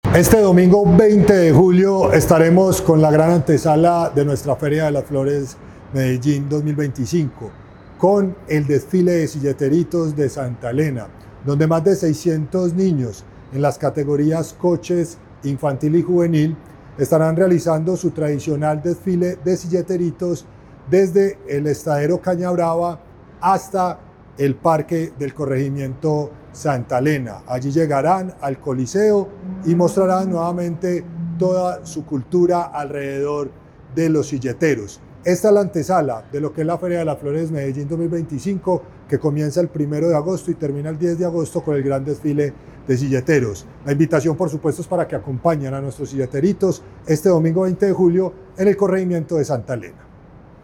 Declaraciones-subsecretario-de-Arte-y-Cultura-Cristian-Cartagena.-Silleteritos.mp3